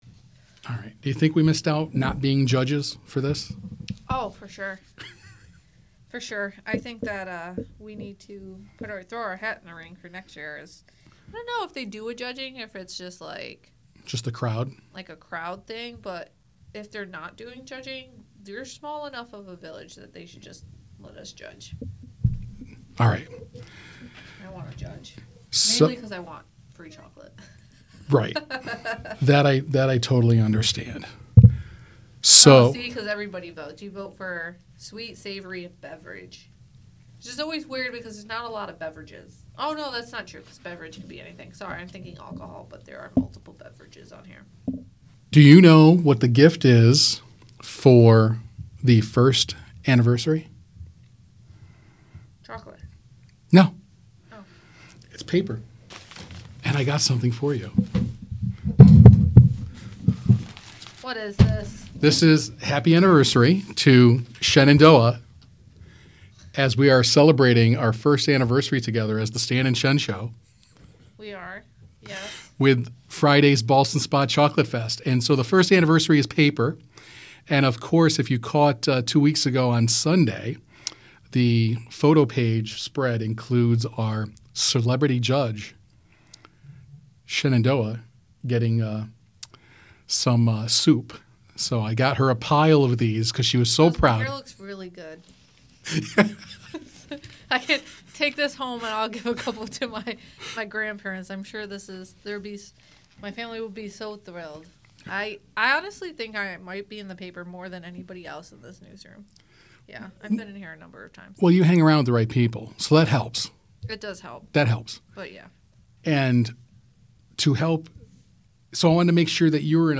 Things came together nicely in the studio